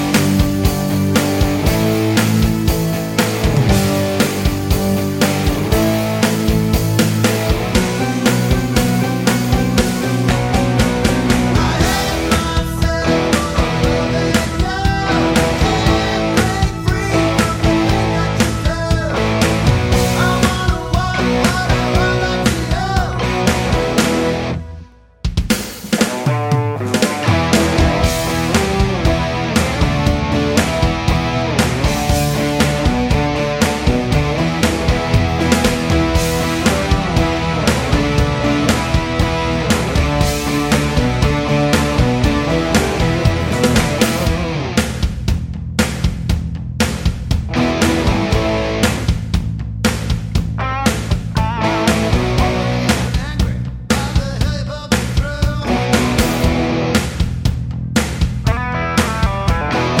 no Backing Vocals Rock 4:08 Buy £1.50